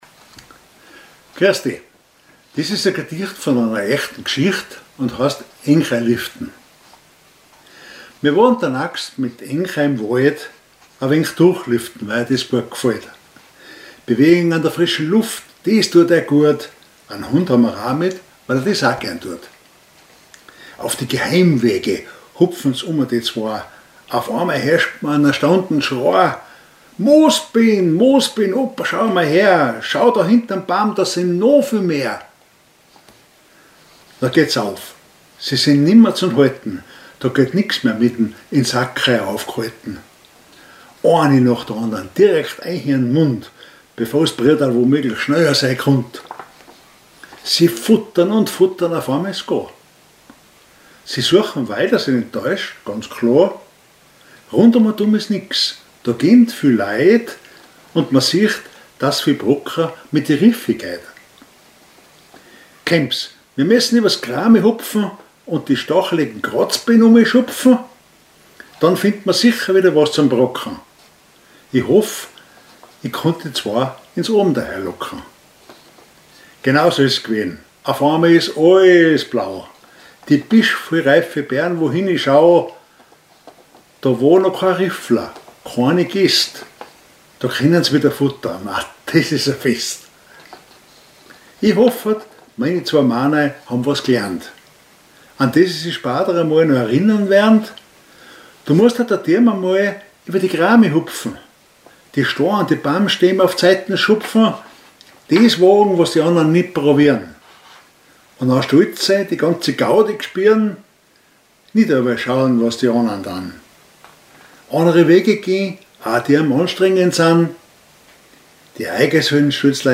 Gedicht Monat September 2025